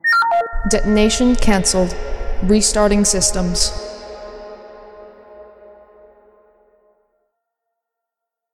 FemaleCanceled.mp3